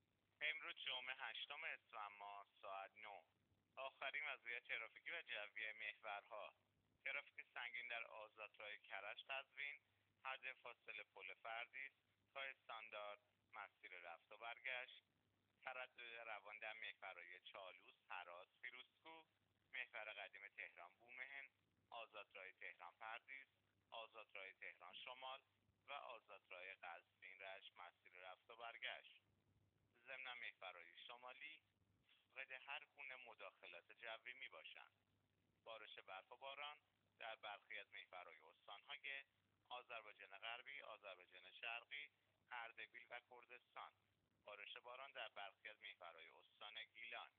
گزارش رادیو اینترنتی از آخرین وضعیت ترافیکی جاده‌ها ساعت ۹ هشتم اسفند؛